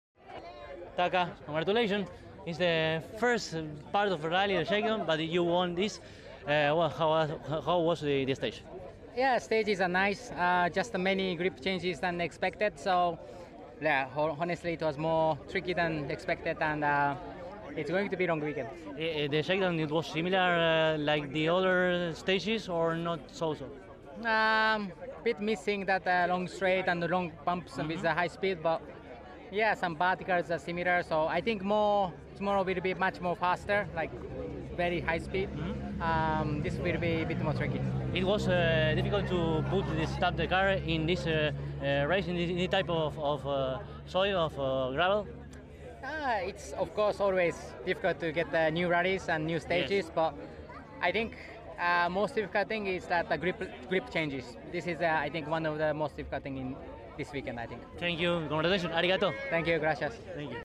La palabra completa de Katsuta, en diálogo exlusivo con CÓRDOBA COMPETICIÓN: